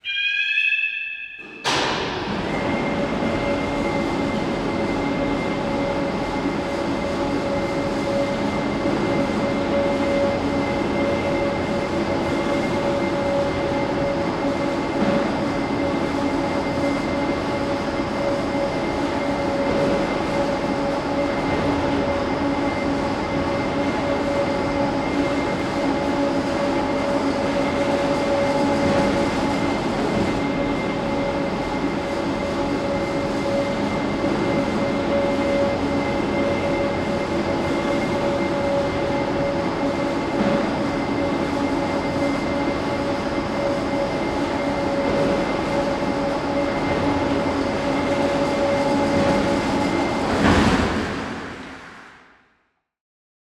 PillarRise.wav